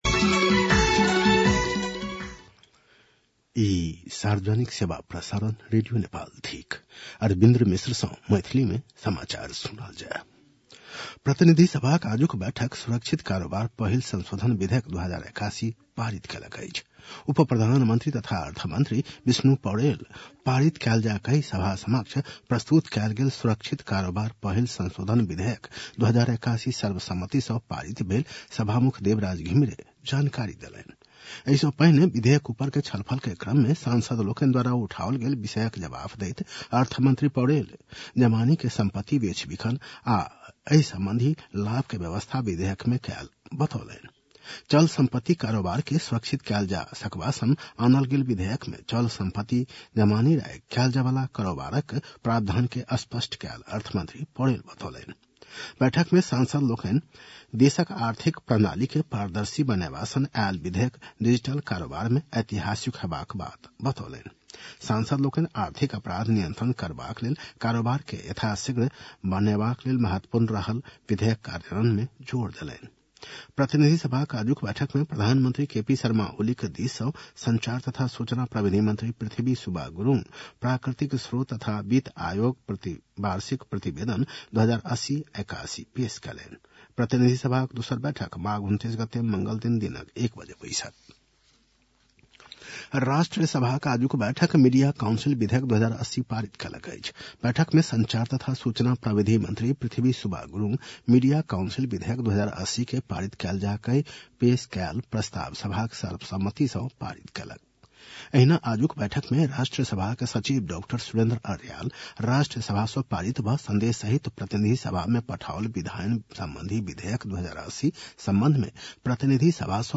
मैथिली भाषामा समाचार : २९ माघ , २०८१
Maithali-news-10-28.mp3